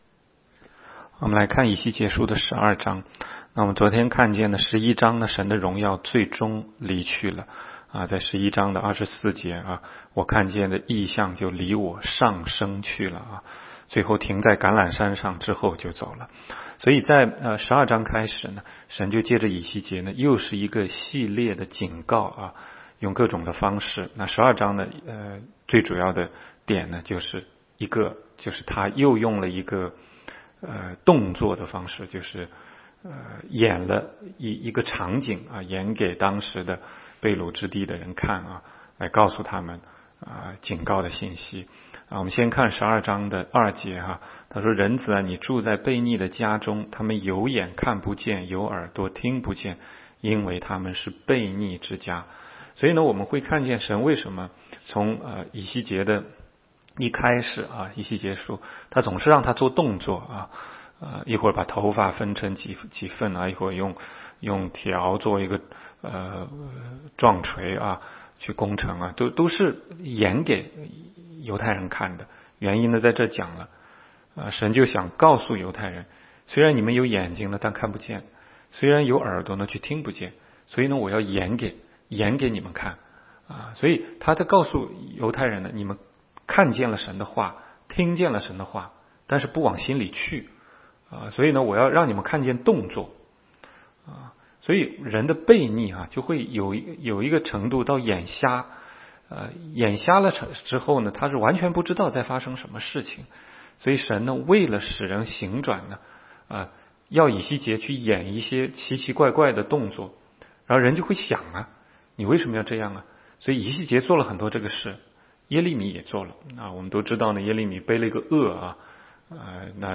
16街讲道录音 - 每日读经 -《以西结书》12章